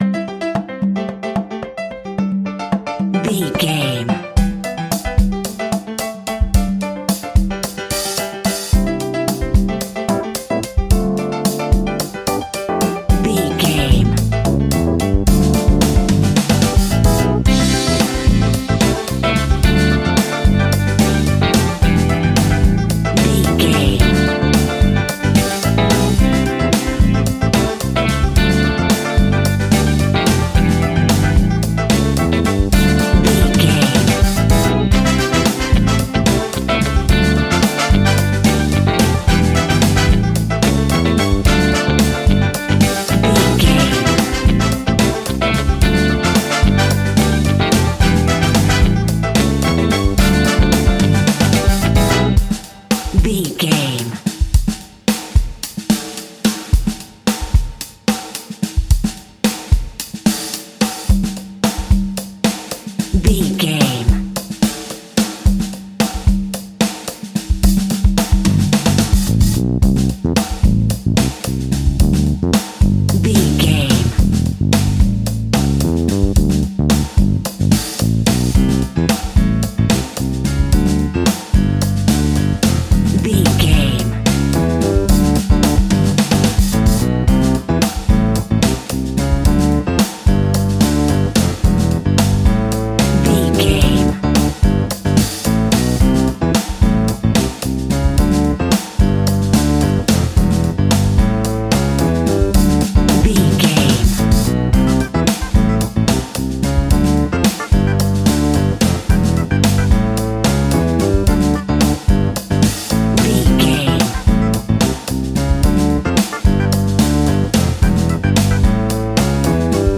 Ionian/Major
latin
salsa
uptempo
brass
saxophone
trumpet
fender rhodes
clavinet